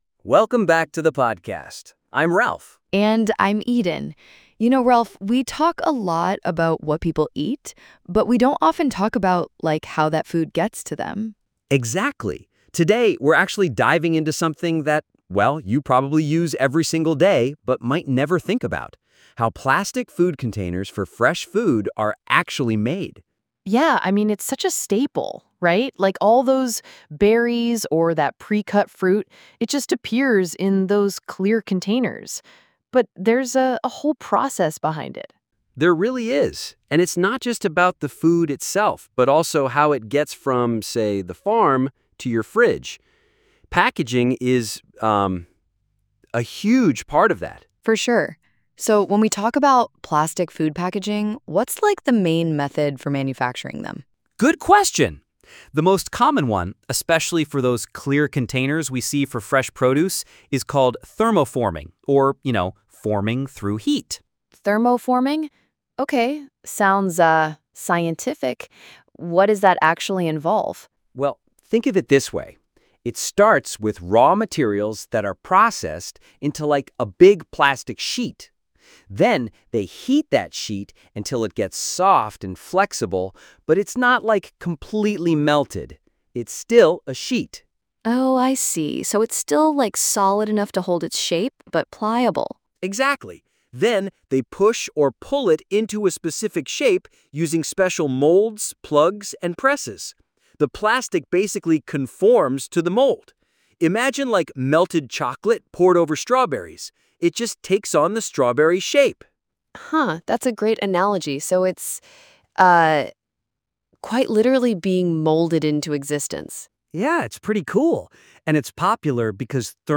This audio uses AI-generated content and media.